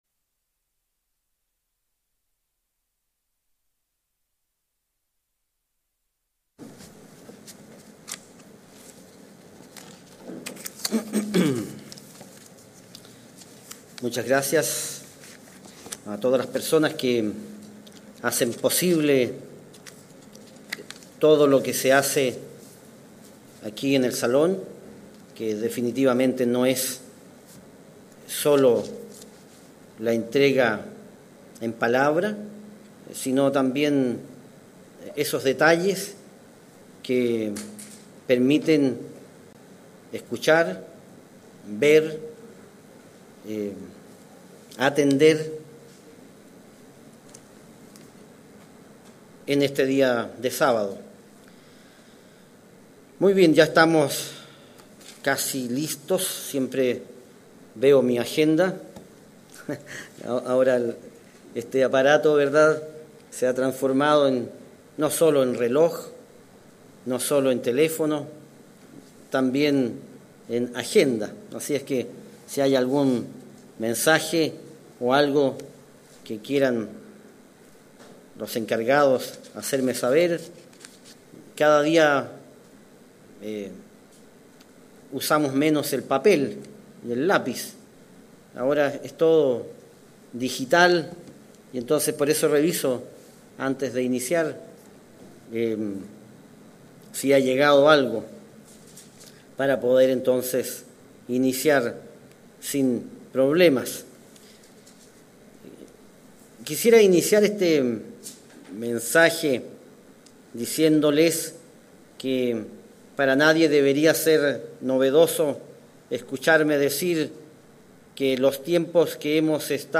Dios, sin embargo, nos da información para ahorrarnos la incertidumbre de la época. Mensaje entregado el 12 de marzo de 2022.